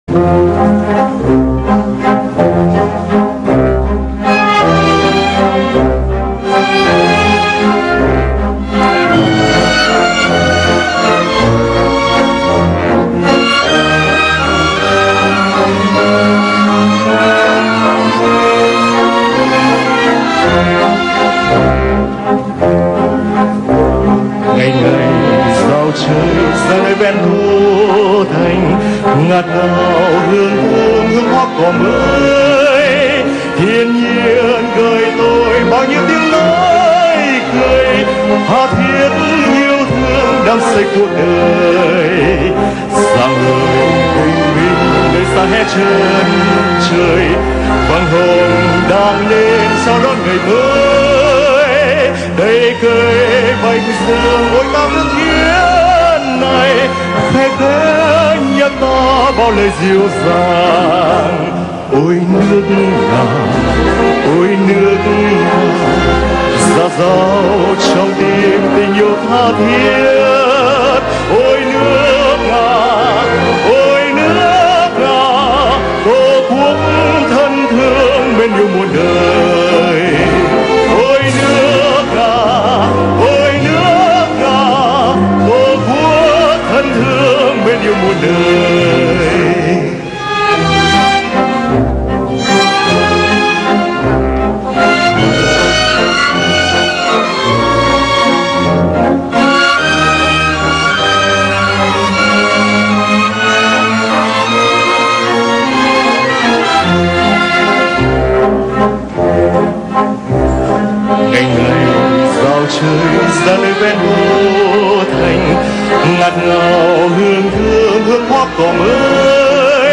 Вьетнамская версия песни